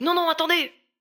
VO_ALL_Interjection_01.ogg